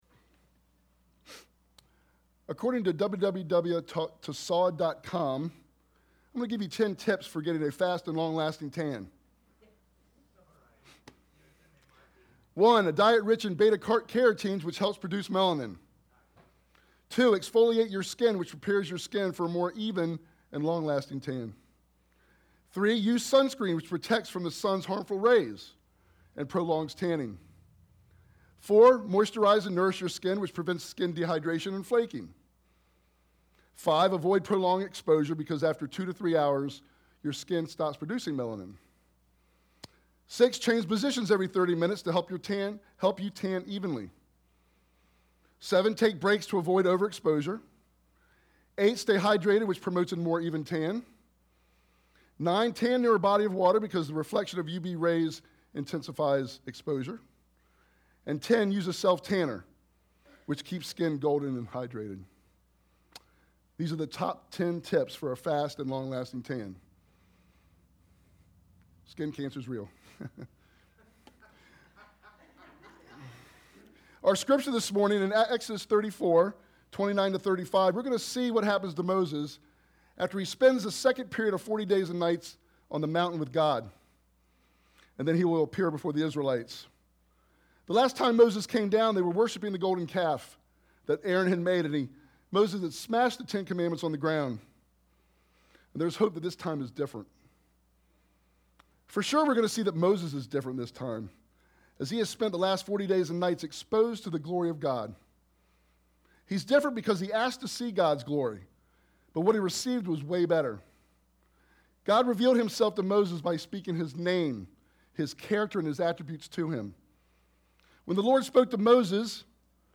Idaville Church » Sermons